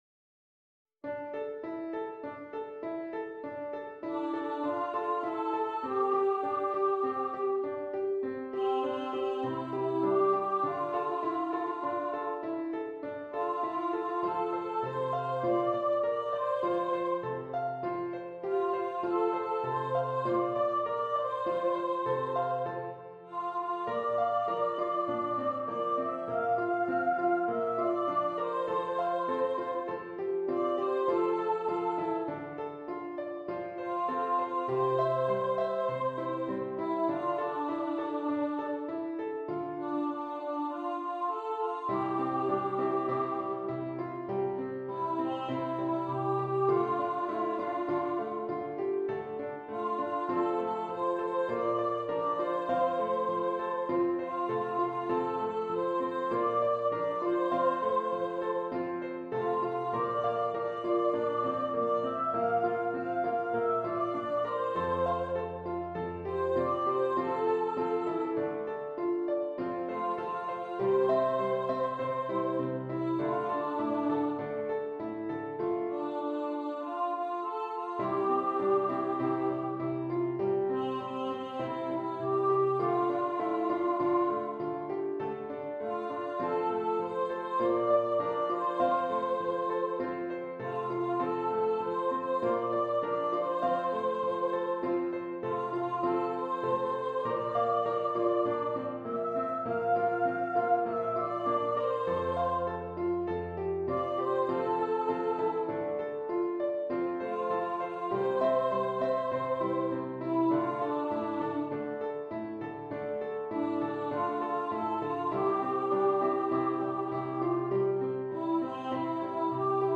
D Minor
Andante